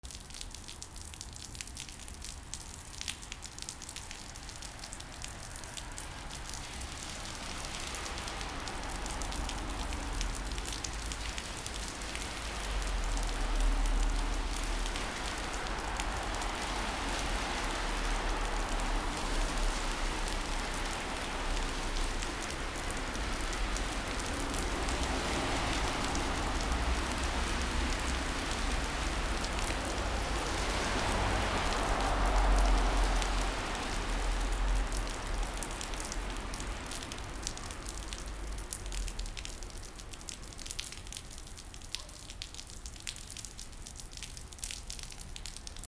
/ A｜環境音(天候) / A-25 ｜雨だれ
雨だれ 雨どいから水が落ちる音
車の音あり 『ボトボト ビチャビチャ』